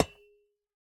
Minecraft Version Minecraft Version latest Latest Release | Latest Snapshot latest / assets / minecraft / sounds / block / copper / break2.ogg Compare With Compare With Latest Release | Latest Snapshot